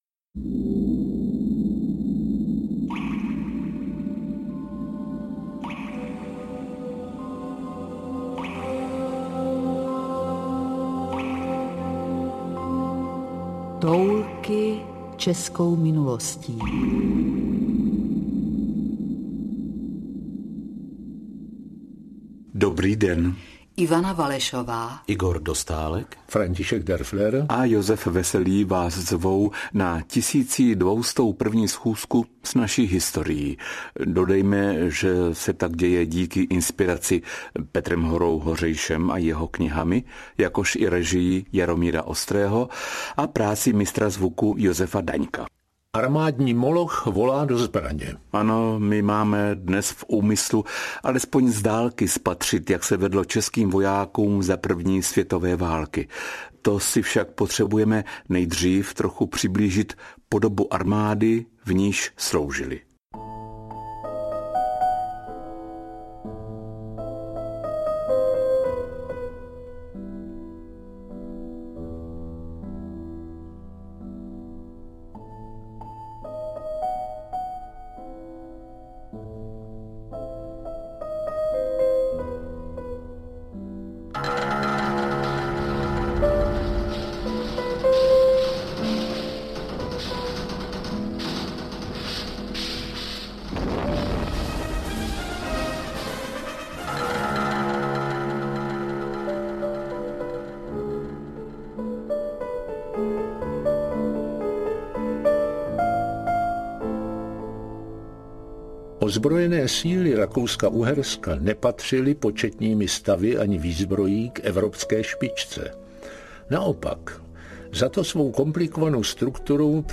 Toulky českou minulostí 1201–1218 audiokniha
Ukázka z knihy